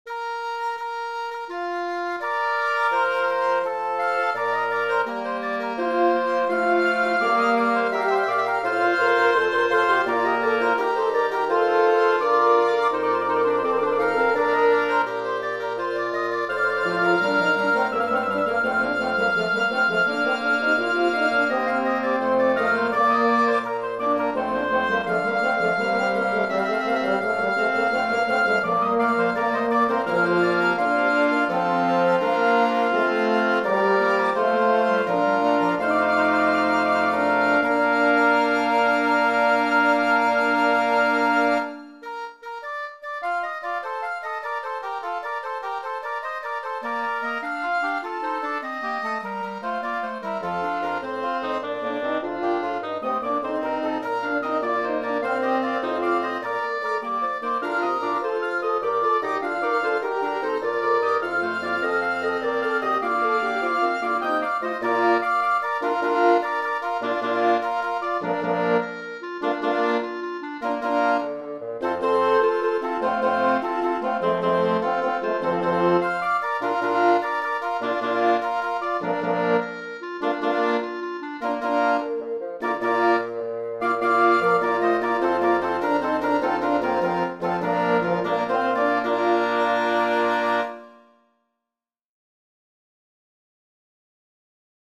Voicing: 5 Woodwinds